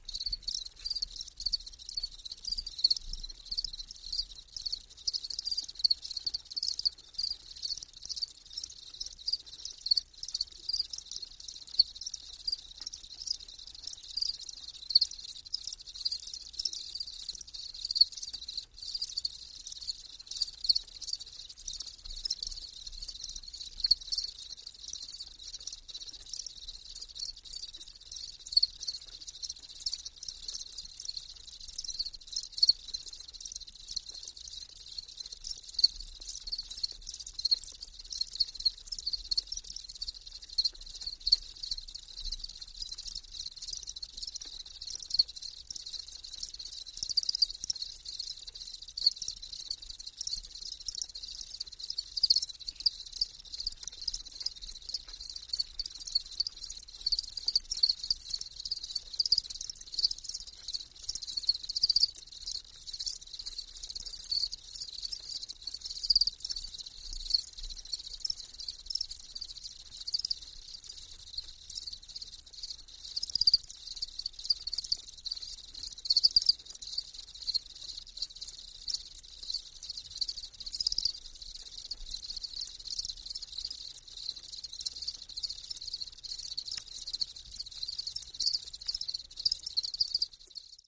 BedCricketsChirpin AZ050402
Insect: Bed Of Crickets Chirping And Clicking. Very Busy. Stereo